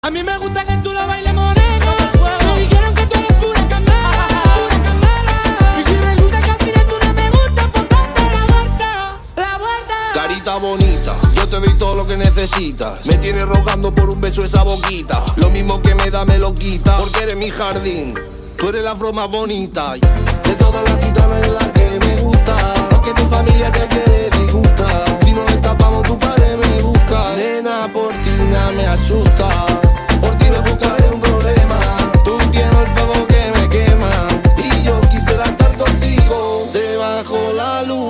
Categoría Rap